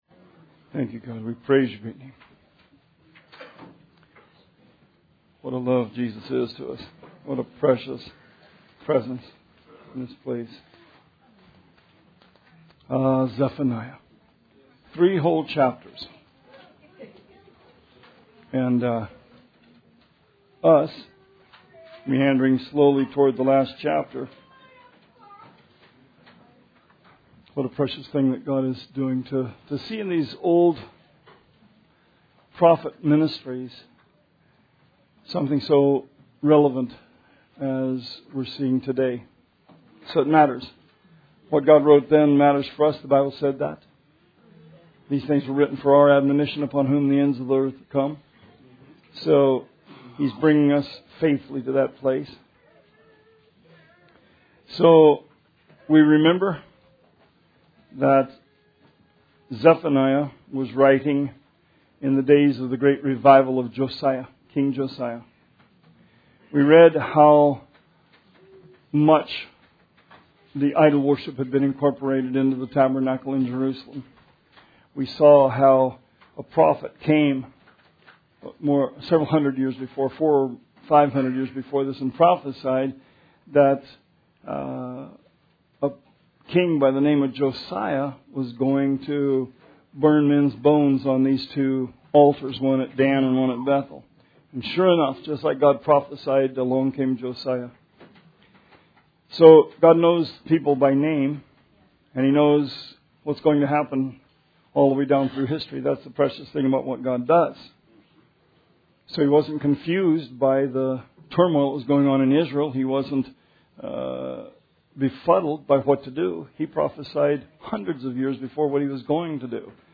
Bible Study 12/10/17